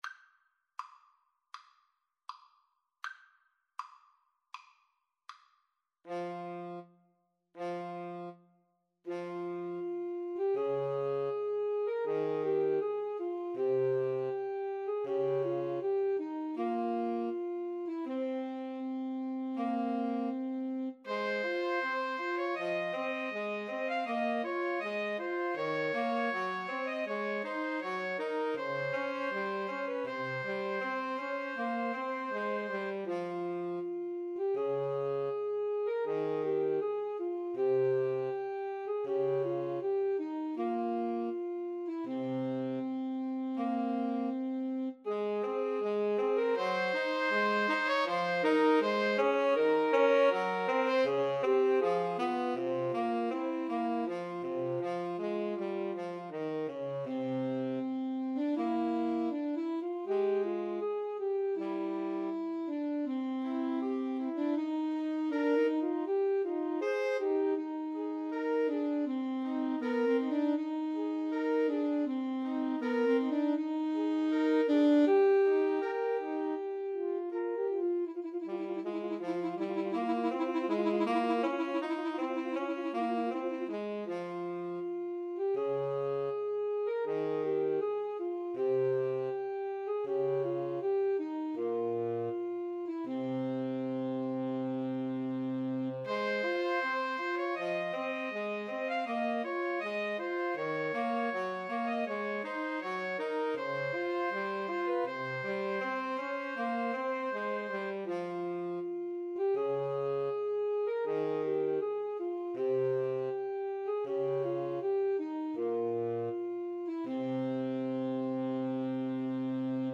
Alto Saxophone 1Alto Saxophone 2Tenor Saxophone
Andante
Classical (View more Classical 2-Altos-Tenor-Sax Music)